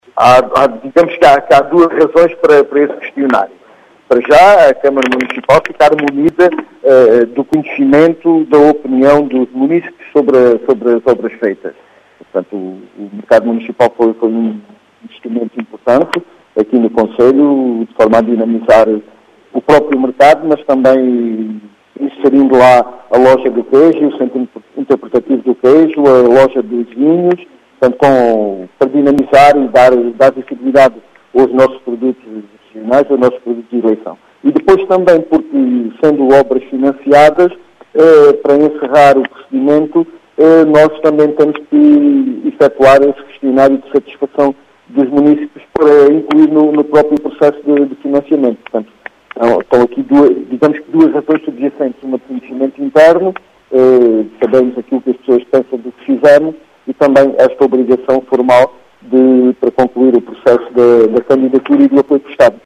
As explicações são de João Efigénio Palma, presidente da Câmara Municipal de Serpa.